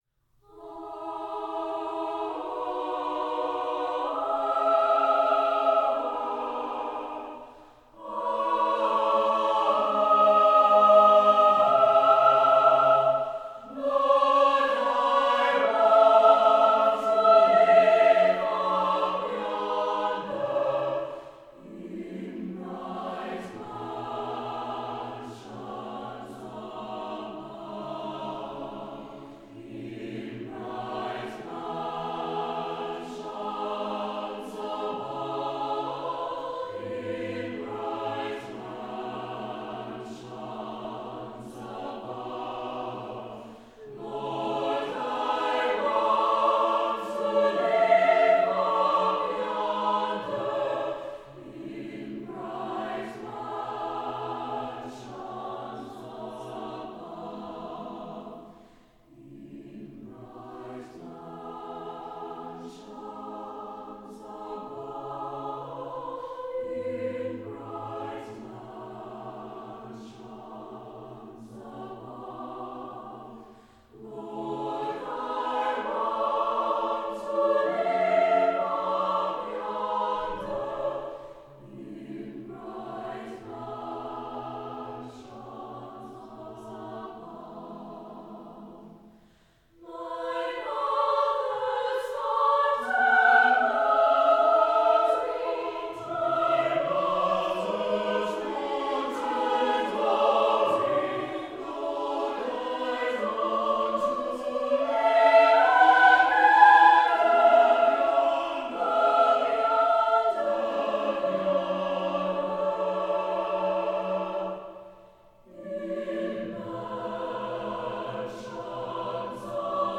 Composer: Spiritual
Voicing: SATB